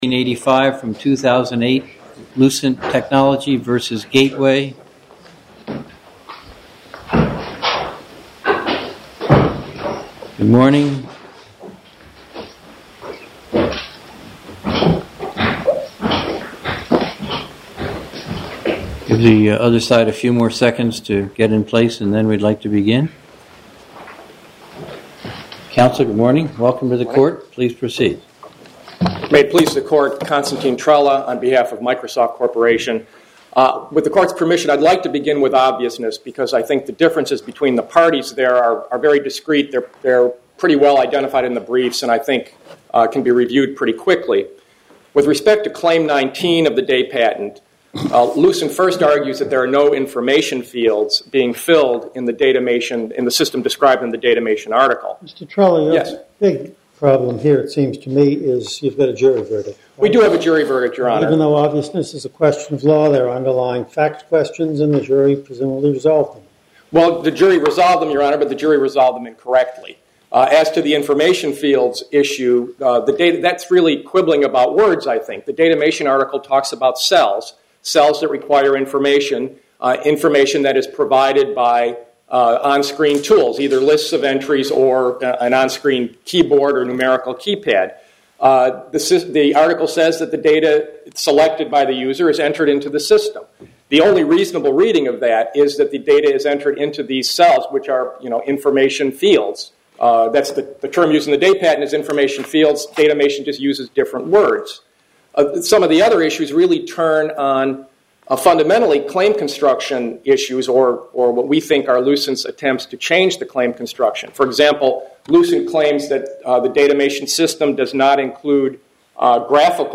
Oral argument audio posted: Lucent Tech v Gateway (mp3) Appeal Number: 2008-1485 To listen to more oral argument recordings, follow this link: Listen To Oral Arguments.